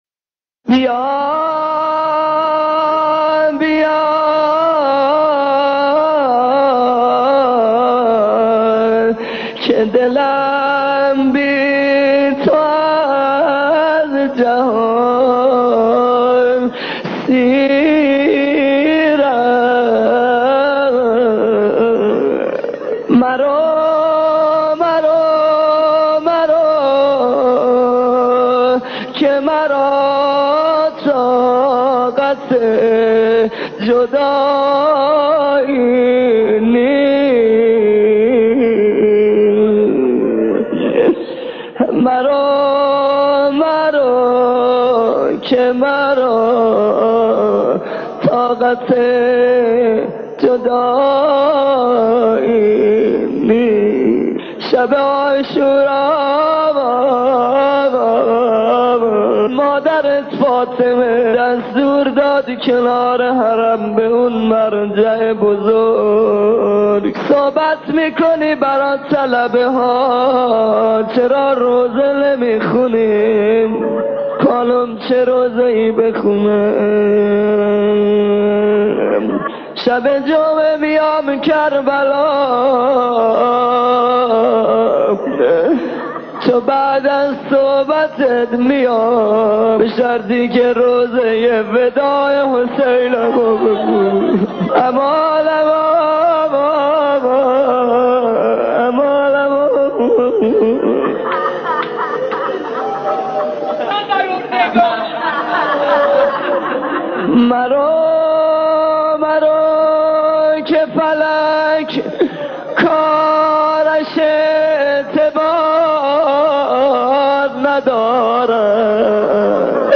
در پرده عشاق، صدای مداحان و مرثیه‌خوانان گذشته تهران قدیم را خواهید شنید که صدا و نفس‌شان شایسته ارتباط دادن مُحب و مَحبوب بوده است.
گریز از شعری در فراق امام زمان (ع) و اشاره به وداع حضرت زینب (س) با سیدالشهدا (ع) و خواندن بخشی از زیارت عاشورا